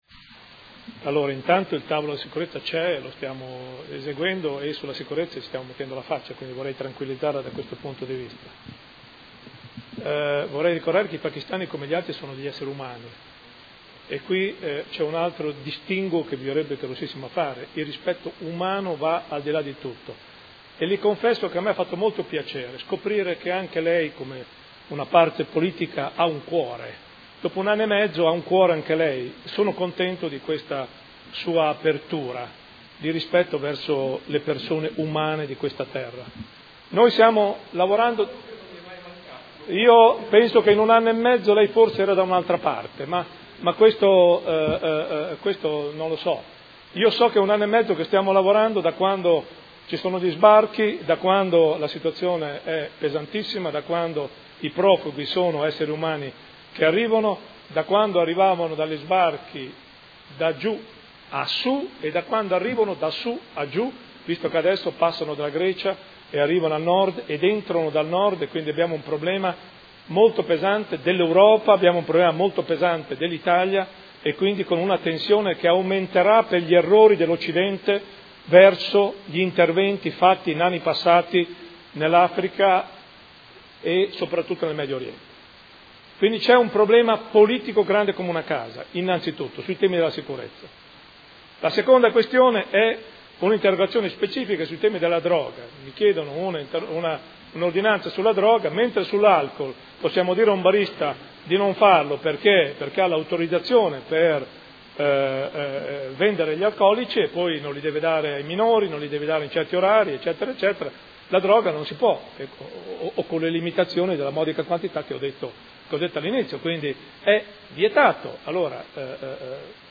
Seduta del 22 ottobre. Interrogazione del Consigliere Pellacani (F.I.) avente per oggetto: Il Signor Sindaco Muzzarelli non crede sia ora di intervenire per far cessare il vergognoso spettacolo quotidiano della movida della droga dietro al Teatro Storchi?.